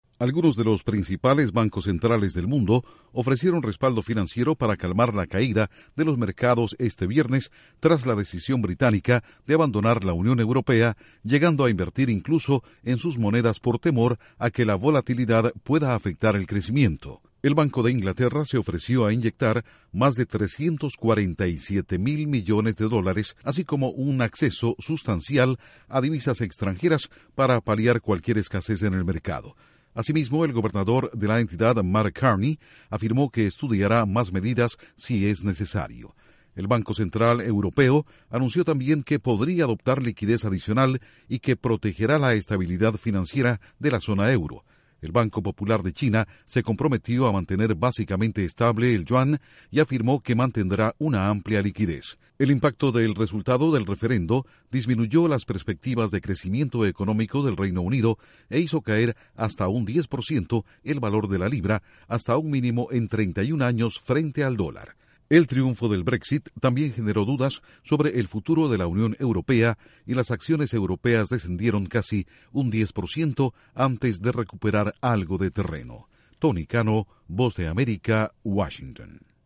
Principales bancos centrales del mundo anuncian medidas para calmar el temor en los mercados tras la conmoción por el 'Brexit'. Informa desde la Voz de América en Washington.